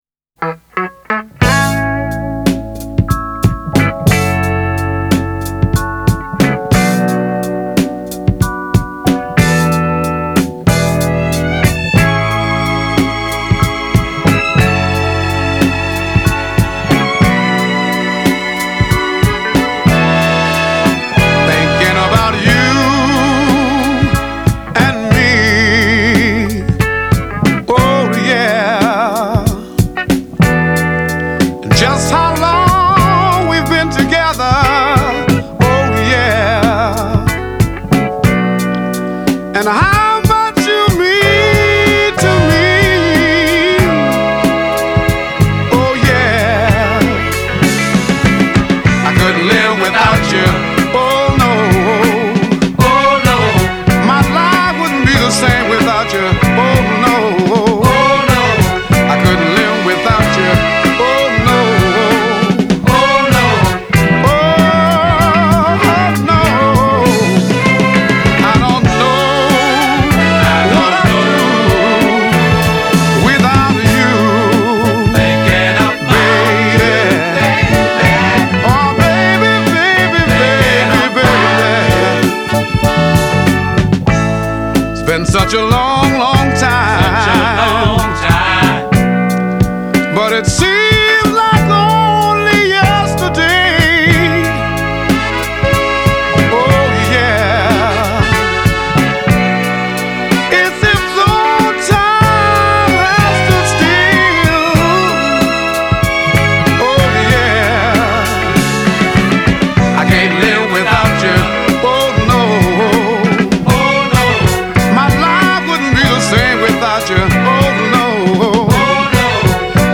the beat-ballad
This soulful pairing